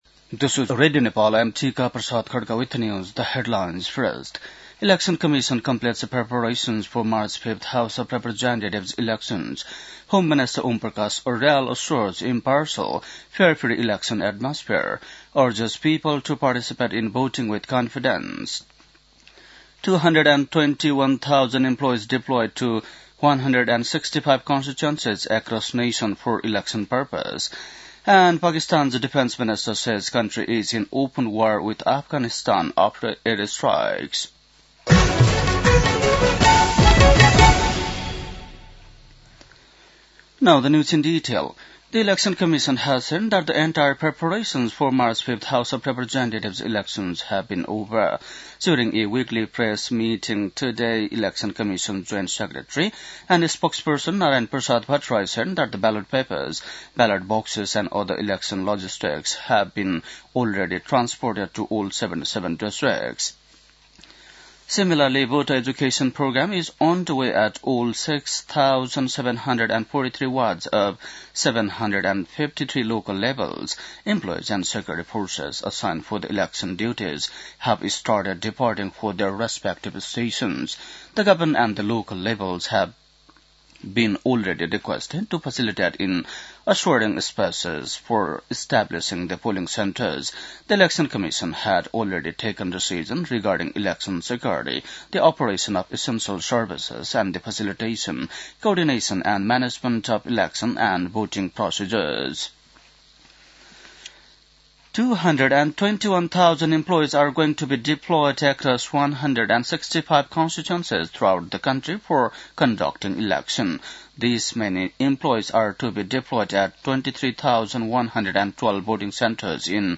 बेलुकी ८ बजेको अङ्ग्रेजी समाचार : १५ फागुन , २०८२